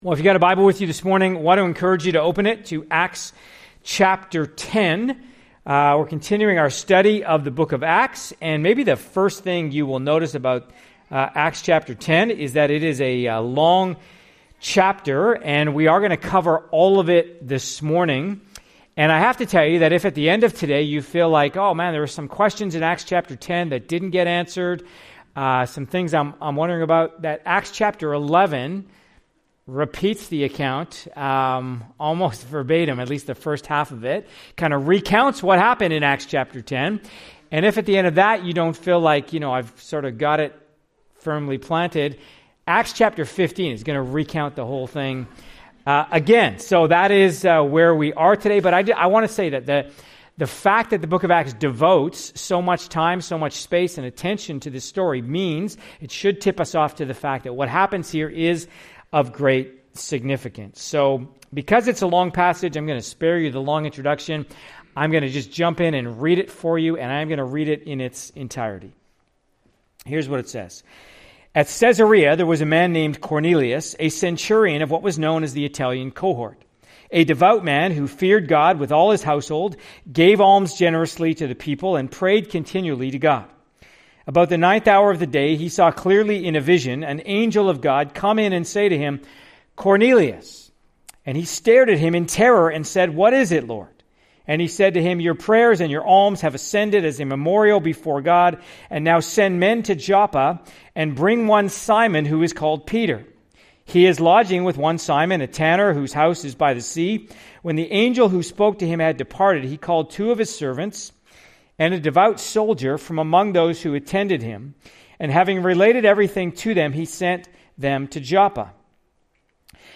Part of our series, ACTS: Mission & Message (click for more sermons in this series).